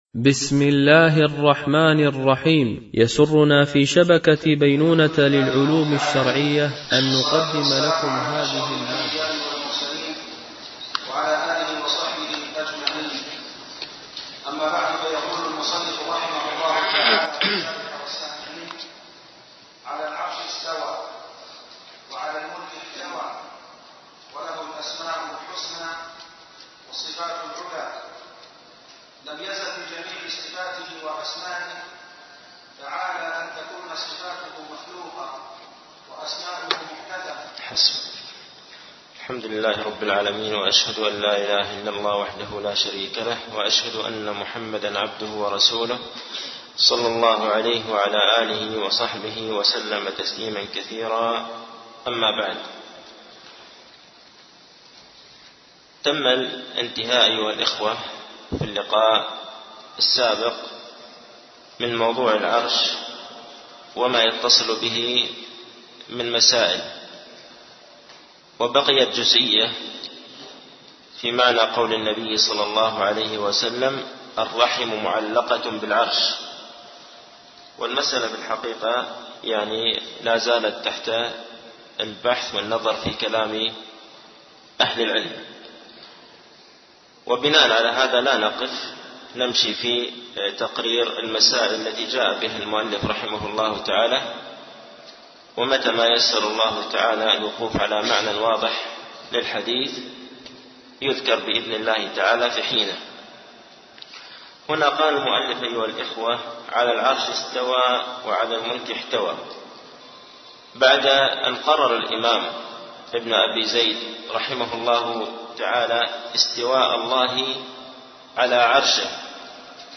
شرح مقدمة ابن أبي زيد القيرواني ـ الدرس الرابع عشر